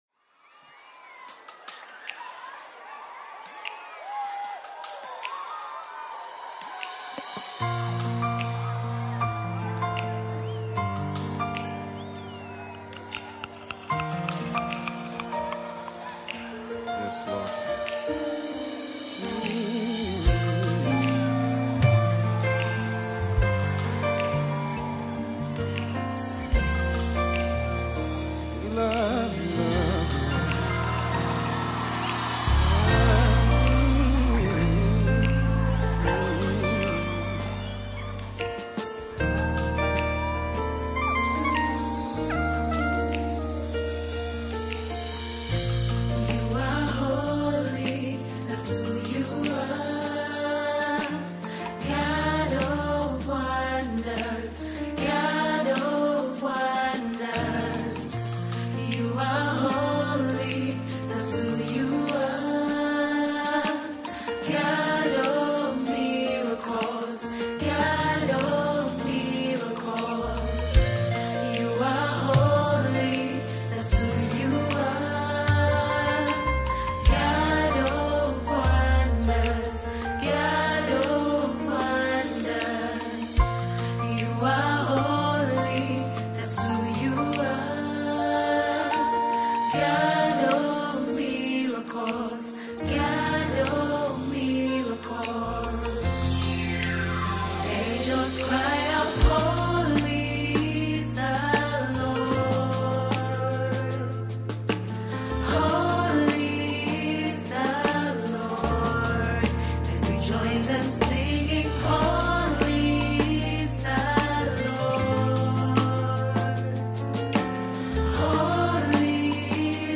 African Music
One of the best Tanzanian worship teams
worship song